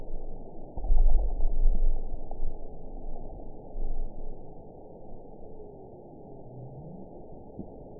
event 921698 date 12/16/24 time 22:29:43 GMT (4 months, 2 weeks ago) score 8.20 location TSS-AB06 detected by nrw target species NRW annotations +NRW Spectrogram: Frequency (kHz) vs. Time (s) audio not available .wav